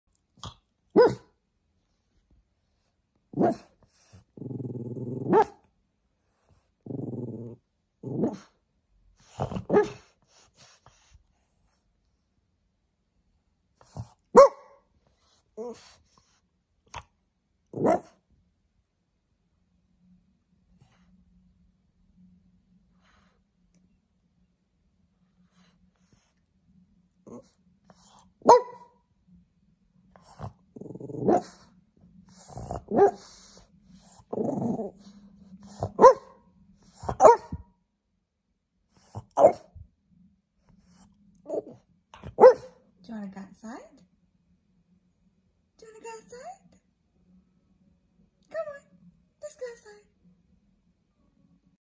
The audio of the barking French Bulldog was downloaded from a video provided by All about frenchies under Youtube's Copyrights Policy.